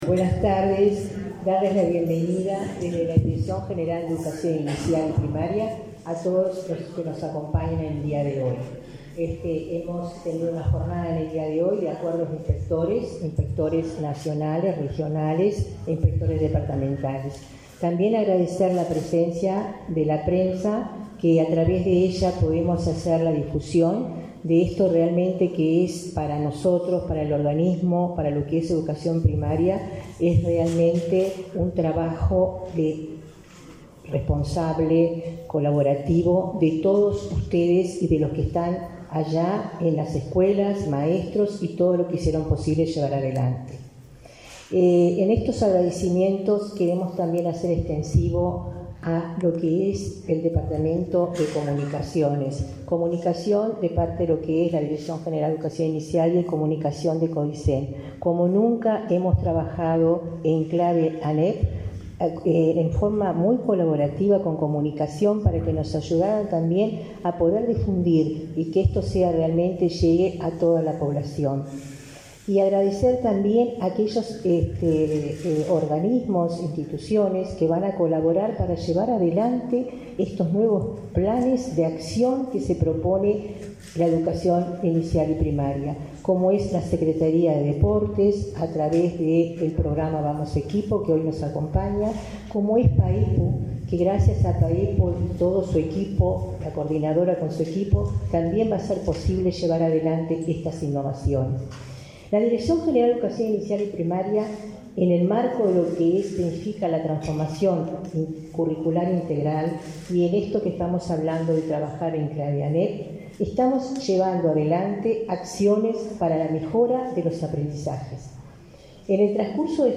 Acto de presentación de plan de acciones para fortalecer aprendizajes en 2023-2024
Participaron en el evento: la directora general de la ANEP, Olga de las Heras, y el presidente de ese organismo, Robert Silva.